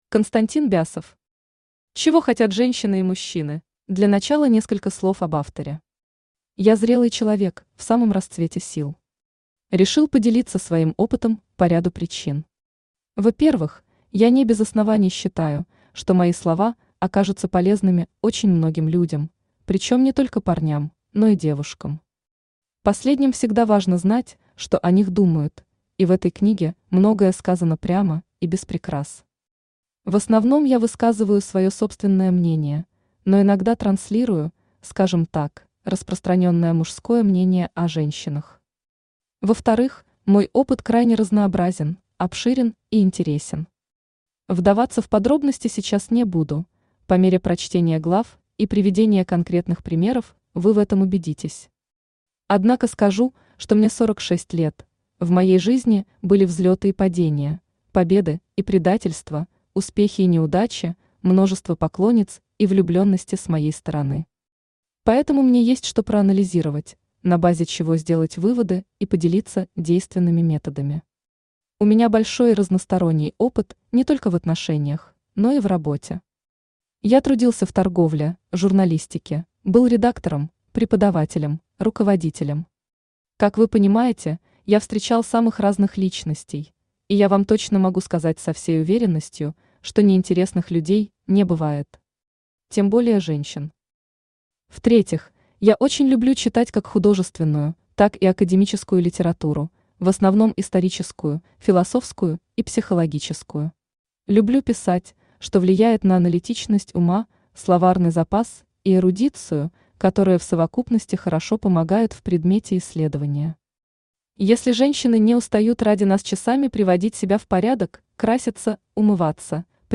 Аудиокнига Мастер пикапа за час, или Как соблазнять девушек и управлять ими | Библиотека аудиокниг
Aудиокнига Мастер пикапа за час, или Как соблазнять девушек и управлять ими Автор Константин Бясов Читает аудиокнигу Авточтец ЛитРес.